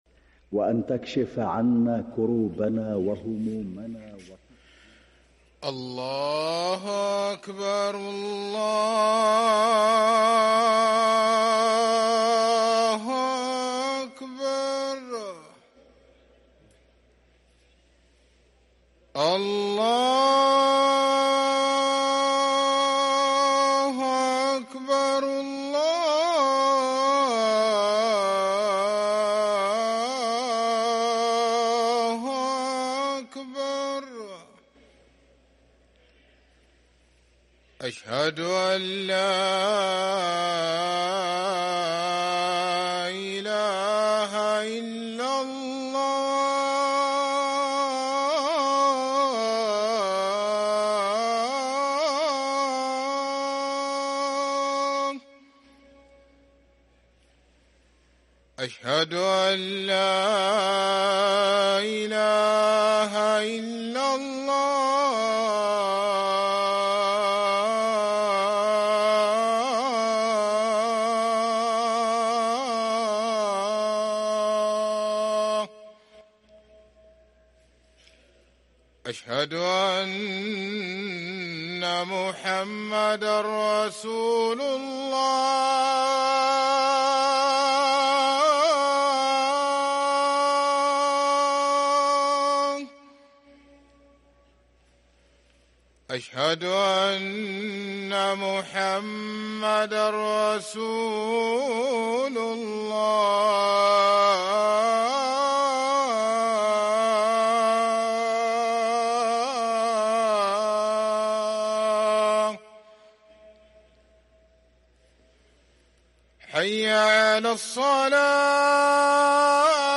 أذان الفجر للمؤذن سعيد فلاته الخميس 26 صفر 1444هـ > ١٤٤٤ 🕋 > ركن الأذان 🕋 > المزيد - تلاوات الحرمين